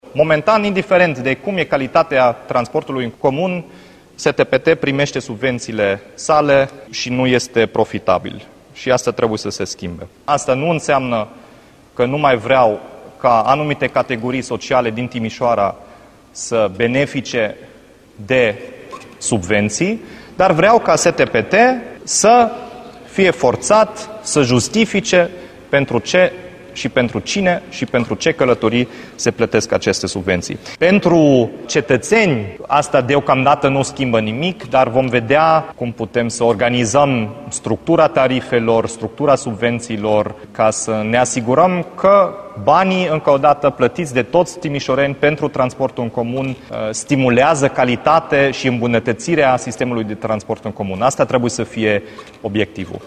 Primarul Dominic Fritz a anunțat că se lucrează la un sistem de lucru prin care STPT să primească subvenție numai dacă oferă servicii de calitate beneficiarilor: